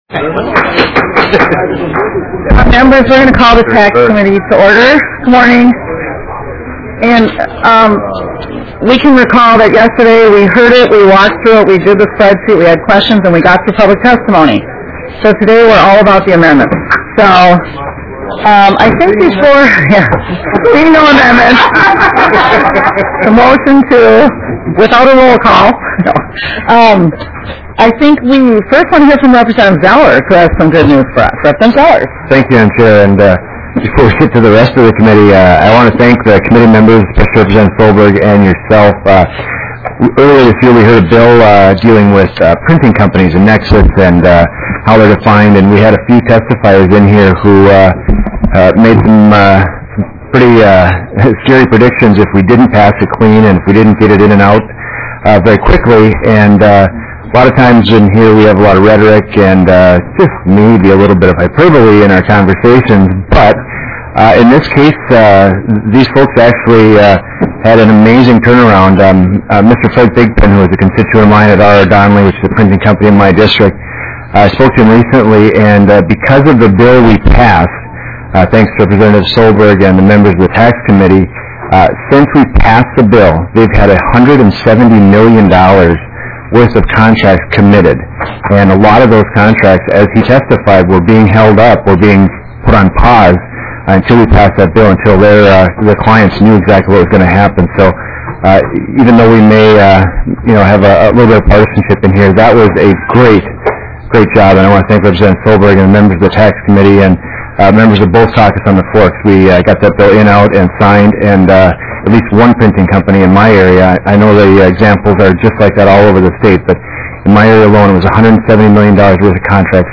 Public testimony will be taken after the recess.